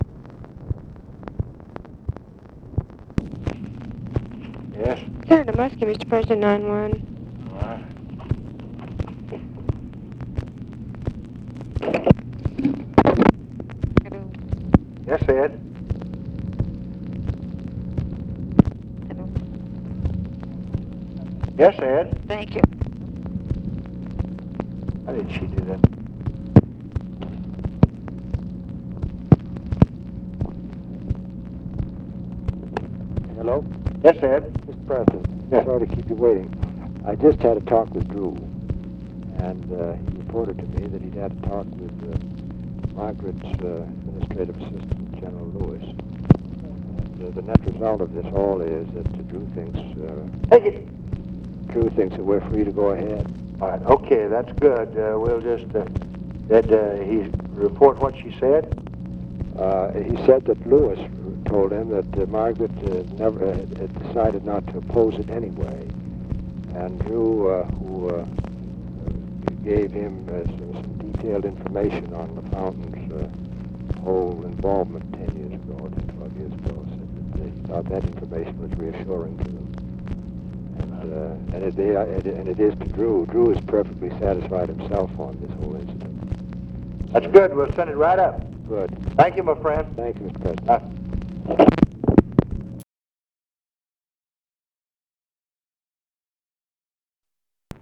Conversation with EDMUND MUSKIE, June 8, 1966
Secret White House Tapes